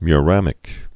(my-rămĭk)